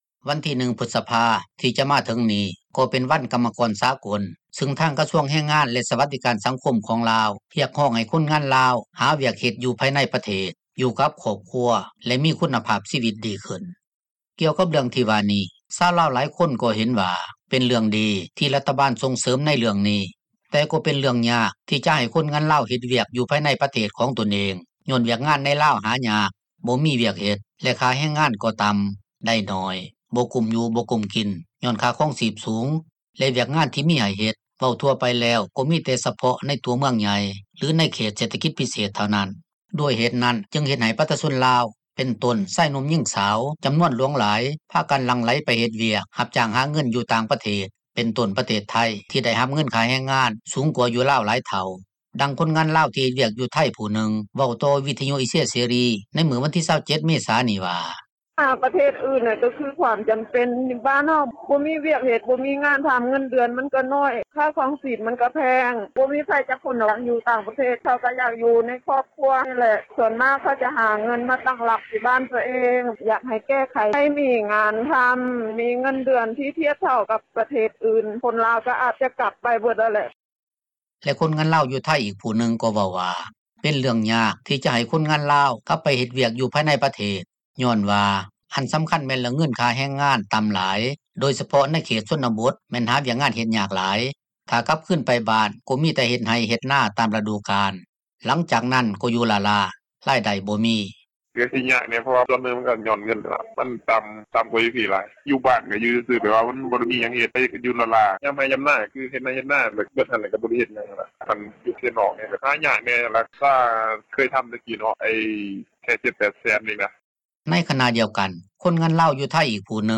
ດັ່ງຄົນງານລາວ ທີ່ເຮັດວຽກຢູ່ໄທຍຜູ້ນຶ່ງ ເວົ້າຕໍ່ວິທຍຸ ເອເຊັຽ ເສຣີ ໃນມື້ວັນທີ 27 ເມສານີ້ວ່າ: